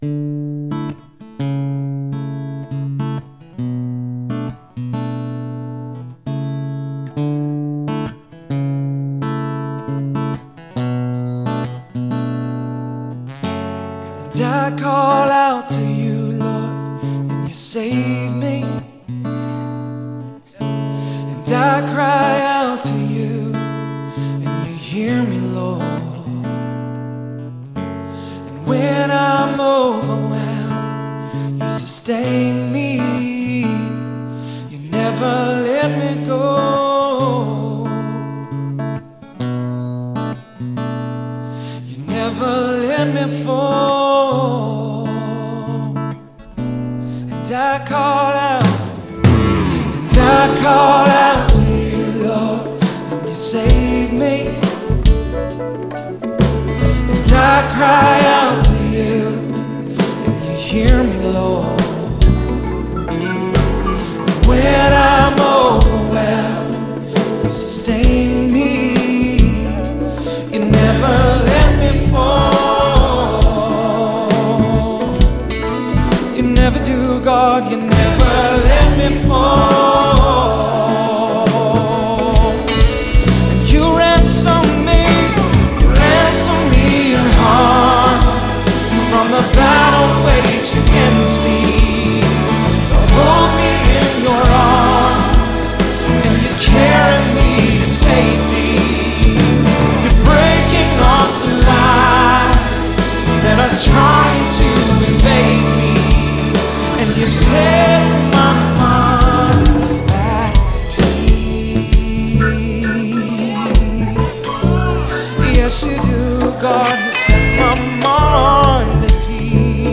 musical interpretation